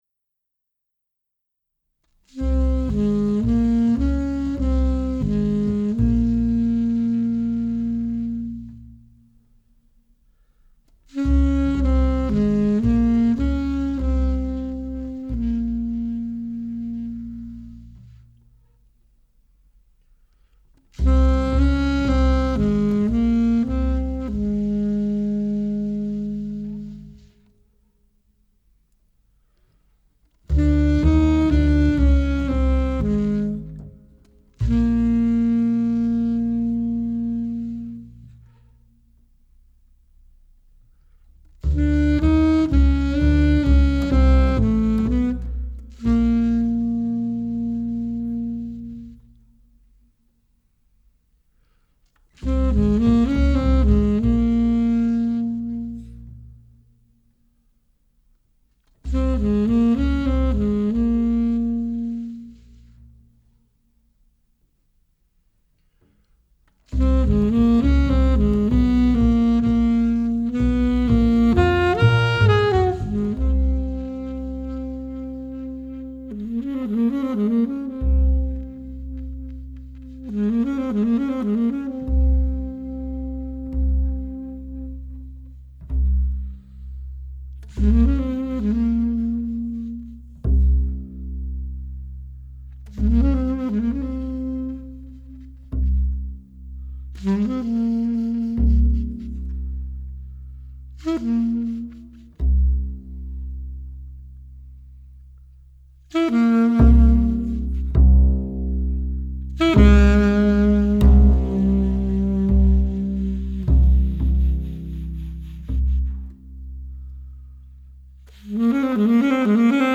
爵士音樂、發燒音樂